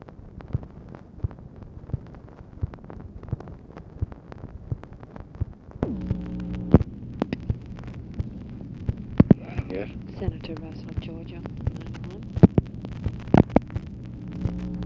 OFFICE SECRETARY ANNOUNCES CALL FROM RICHARD RUSSELL BUT CONVERSATION IS NOT RECORDED
Secret White House Tapes